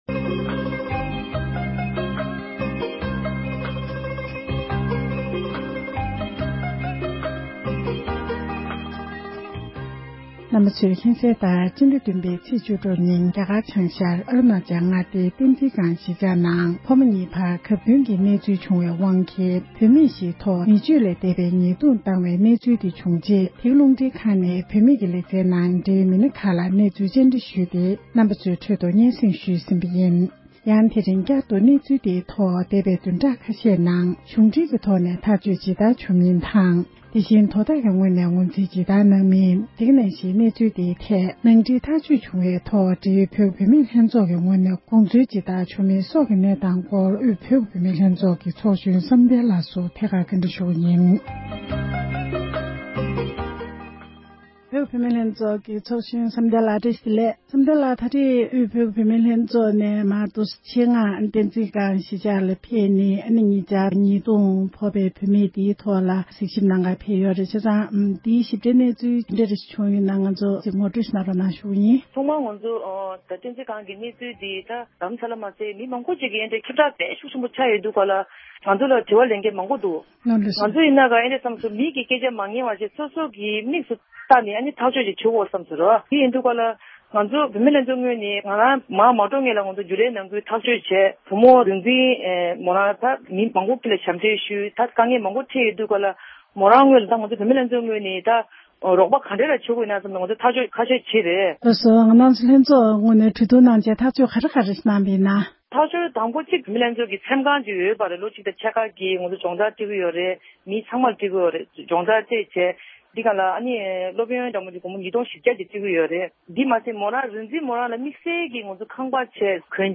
གནས་ཚུལ་བཅར་འདྲི